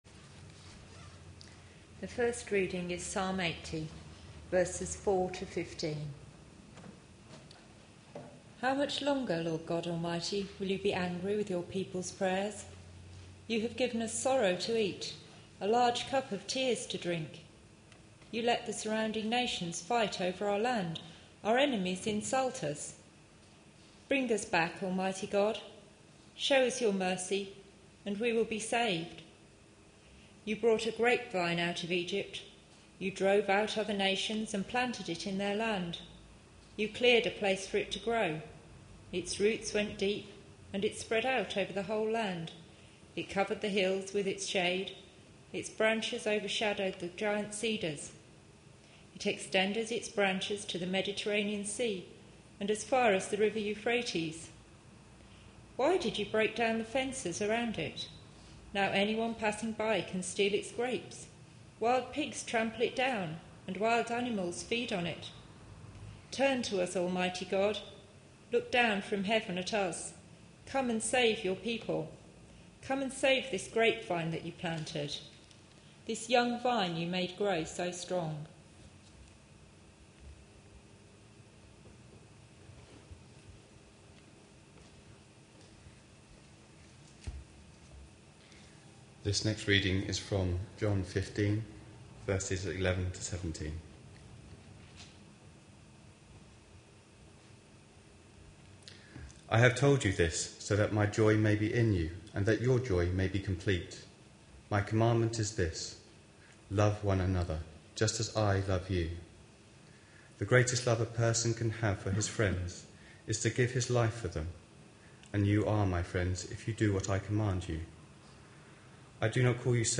A sermon preached on 29th January, 2012, as part of our Looking For Love (6pm Series) series.